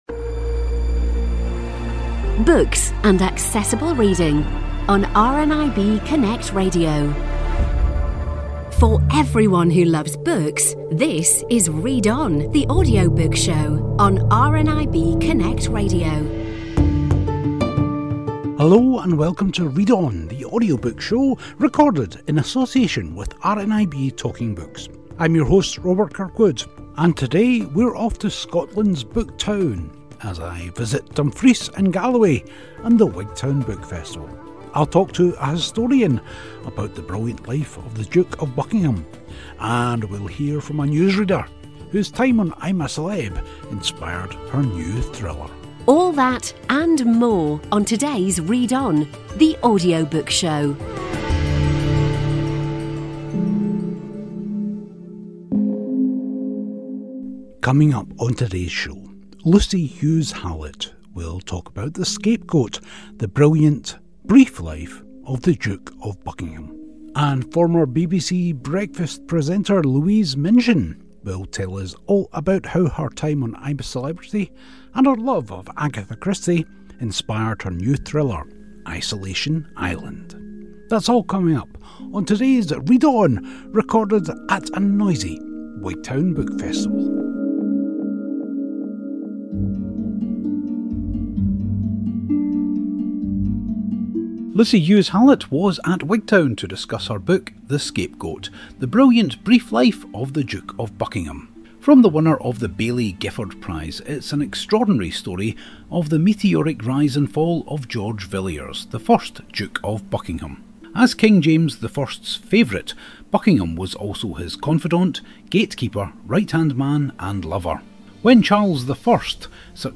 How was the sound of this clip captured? A weekly show all about audiobooks recorded at the RNIB Talking Book studios. We talk to your favourite authors and narrators, along with reviews and news about new audiobooks.